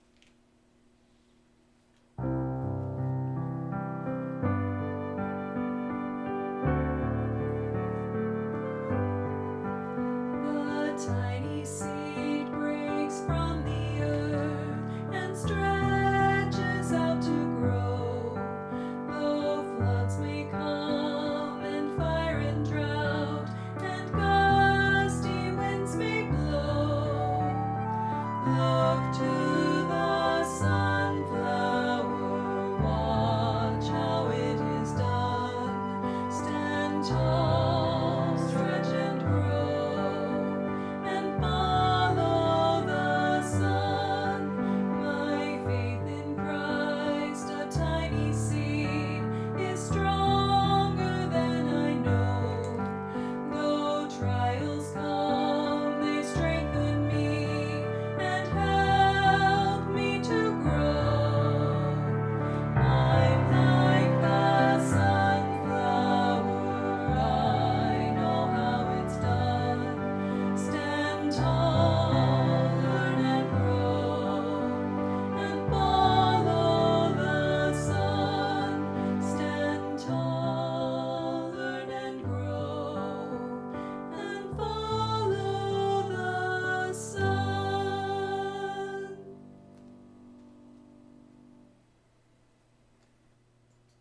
Vocal Solo, Young Women Voices
Medium Voice/Low Voice